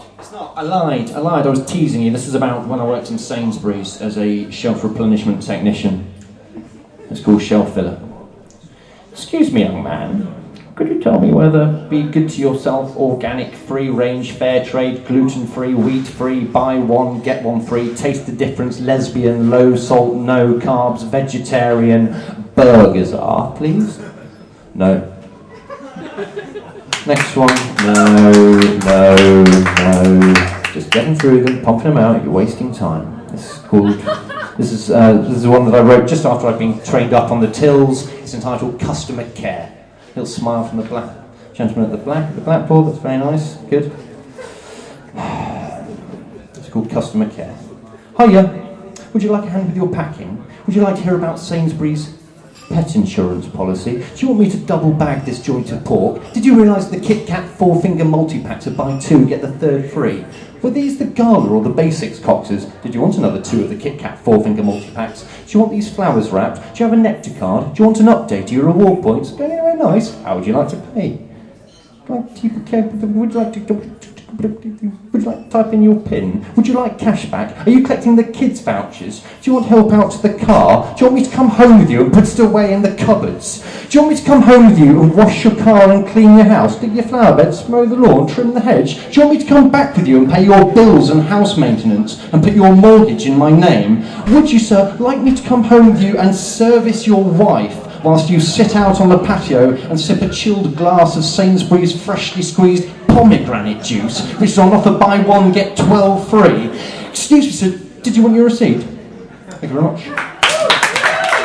performing at James St Tavern, Oxford